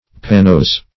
Search Result for " pannose" : The Collaborative International Dictionary of English v.0.48: Pannose \Pan"nose`\, a. [See Pannus .]
pannose.mp3